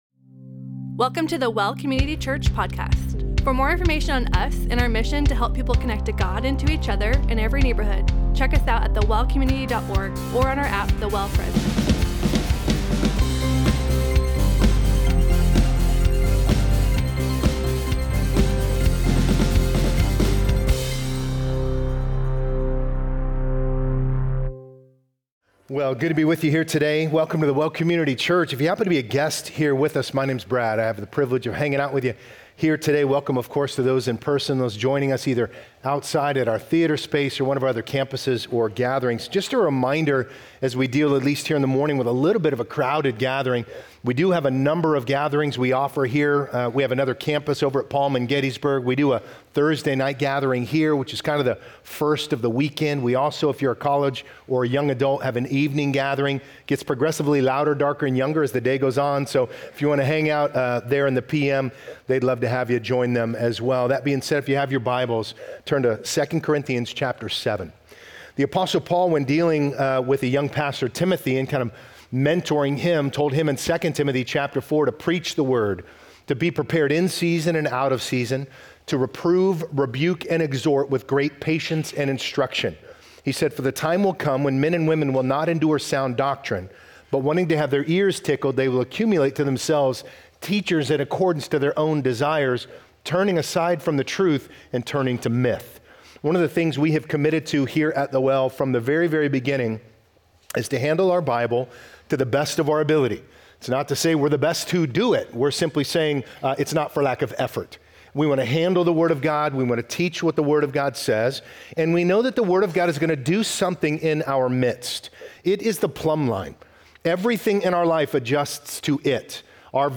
The Well: Sermon Audio
The Well generally teaches directly from Scripture in an expositional style. This feed is an archive of past sermons.